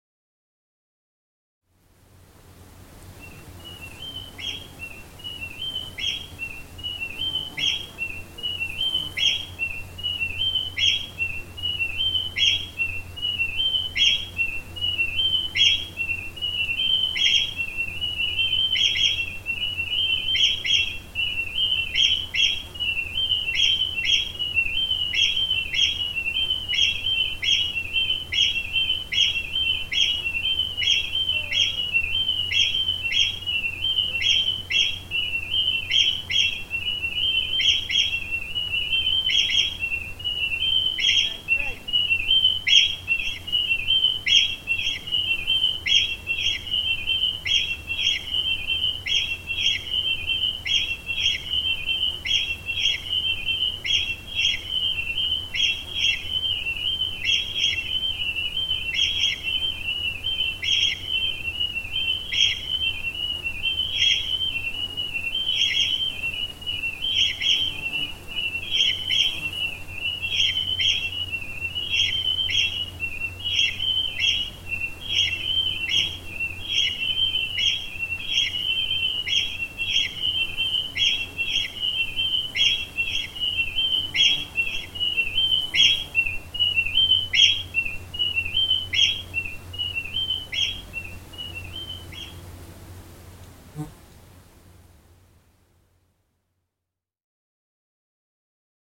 Central to the composition is a short, repeating birdsong motif which fractures into shifting patterns and returns to its original form by way of Steve Reich inspired phase shifting techniques. I wanted to preserve the character of the original recording and the sounds of wind in leaves, insects and human voices serve as an audio environment for the phasing birdsong to inhabit.